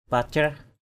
/pa-crah/ (đg.) bắt bồi thường. pacrah kamei F%cH km] bắt bồi thường duyên cho con gái.